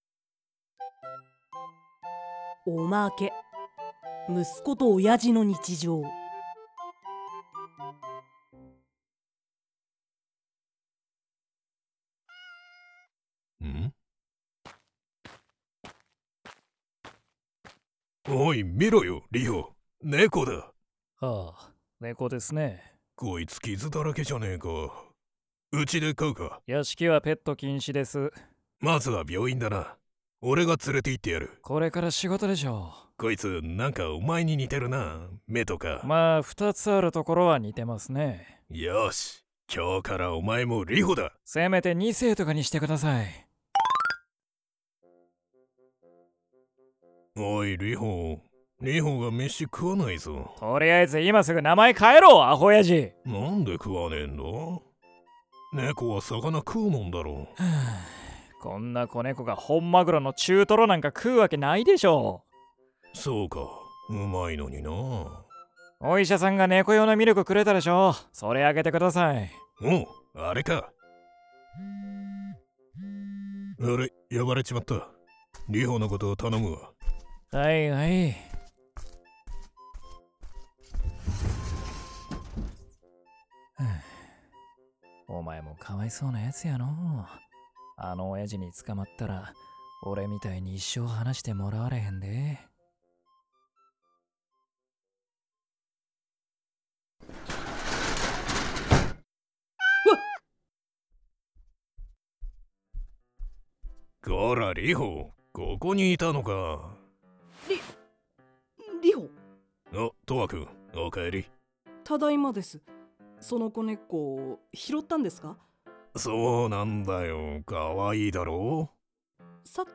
極道さんと霊媒師くん おまけ「親父と息子の日常」 - ASMR Mirror